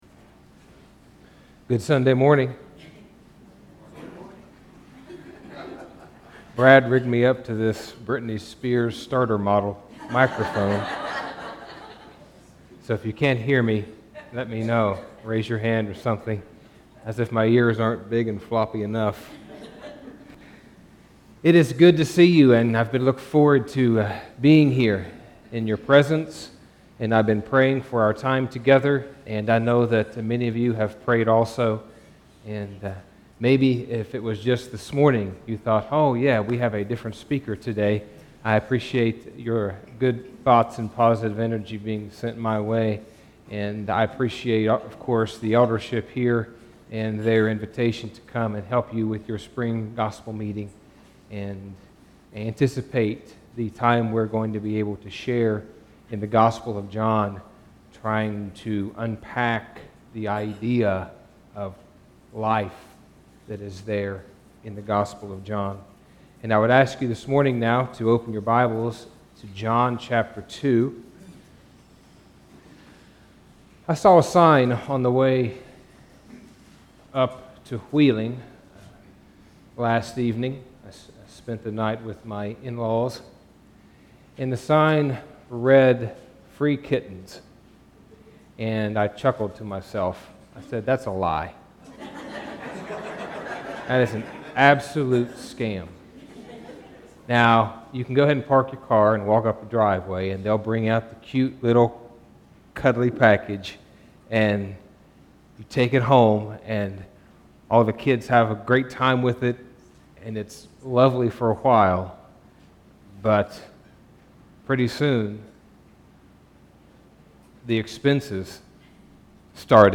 Sunday morning Bible Study